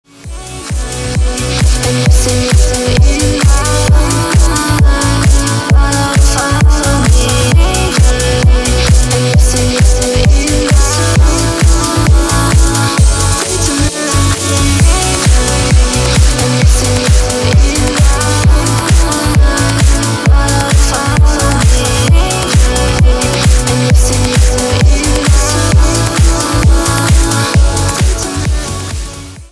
• Качество: 320, Stereo
красивые
The Best Trance